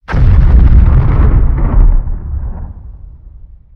probeboom.ogg